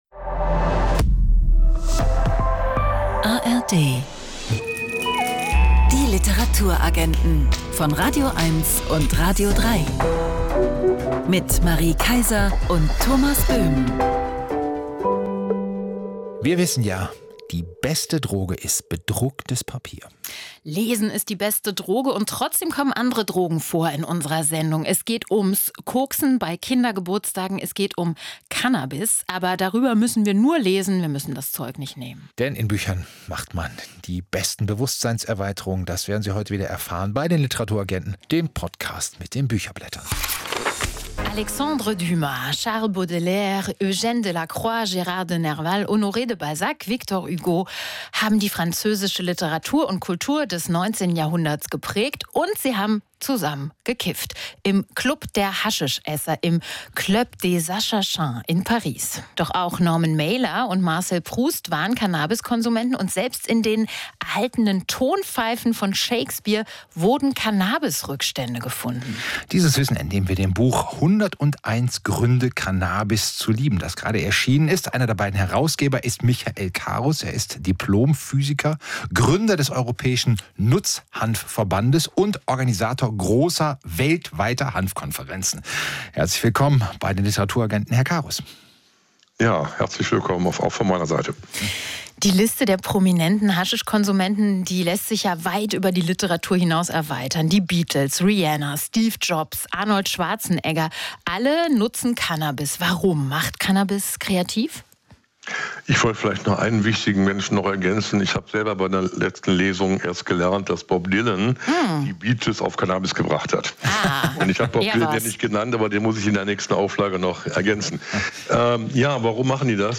All die kennen die Literaturagenten auf radioeins und radio3 auch – und machen mit ihnen jeden Sonntag ein Büchermagazin mit Charme, Wissen und Leidenschaft: Die wichtigsten Neuerscheinungen werden vorgestellt in Gesprächen und Rezensionen.